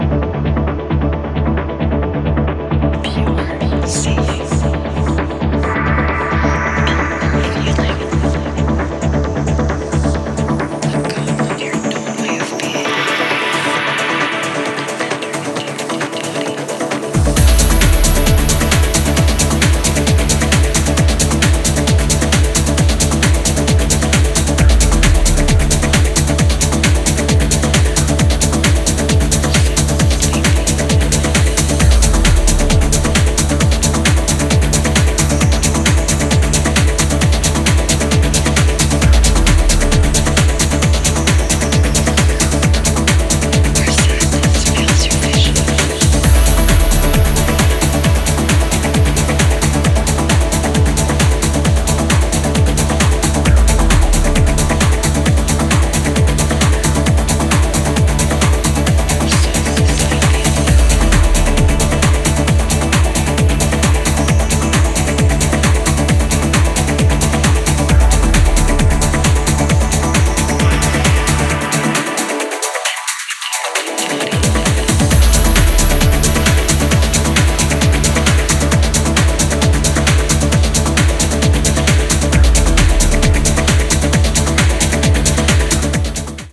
かっちりと刻まれる4/4キックと空間に溶け込むトレモロリフが陶酔感溢れる